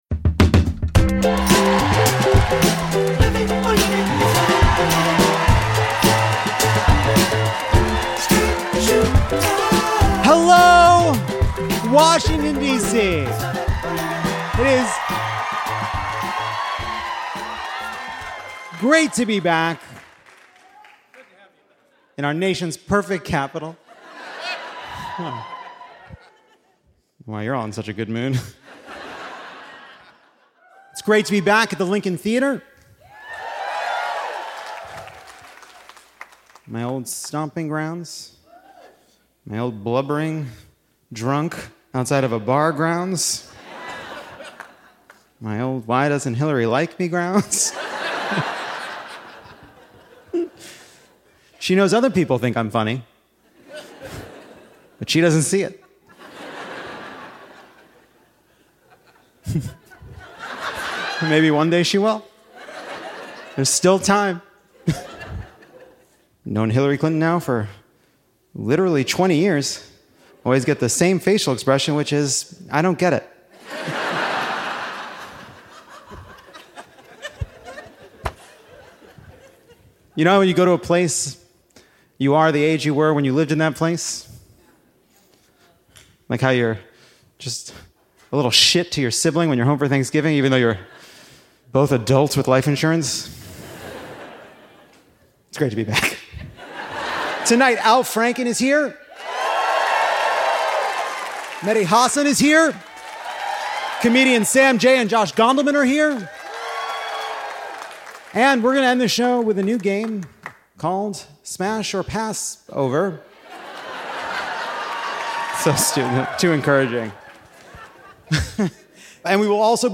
Live from our nation’s perfect capital, Al Franken stops by to look at the latest batch of lying liars.